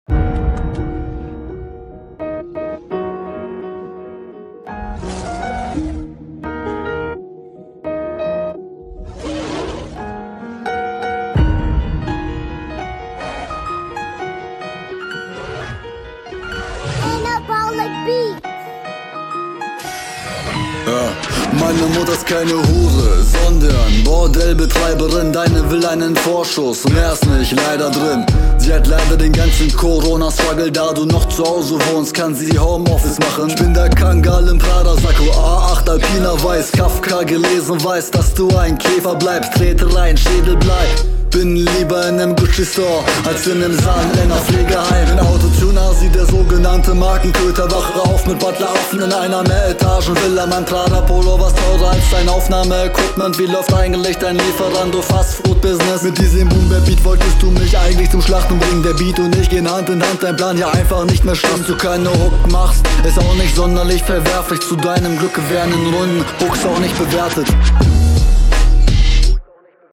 Woah, hier kommt deine Stimme ja mal richtig fett.